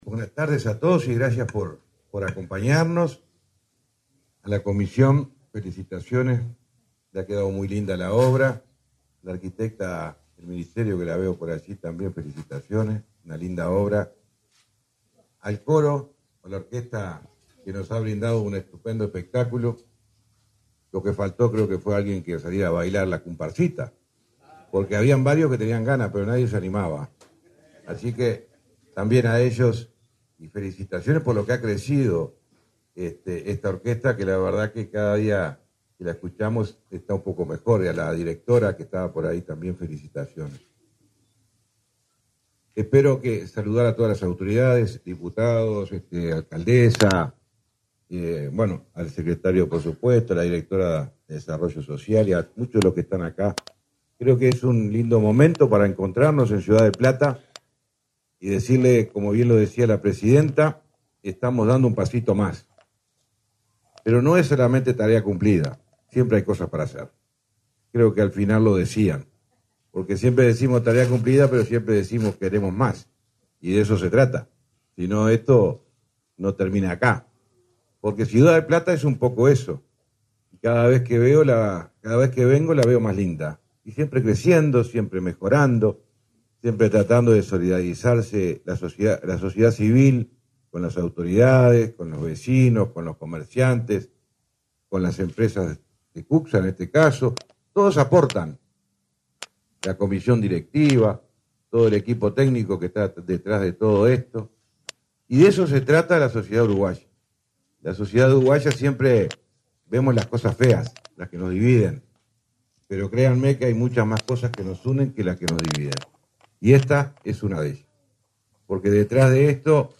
Palabras del titular del MTOP, José Luis Falero
Palabras del titular del MTOP, José Luis Falero 11/10/2024 Compartir Facebook X Copiar enlace WhatsApp LinkedIn El Ministerio de Transporte y Obras Públicas (MTOP) inauguró, este 11 de octubre, obras por convenios sociales en San José. En el evento, el titular de la cartera, José Luis Falero, destacó los trabajos realizados.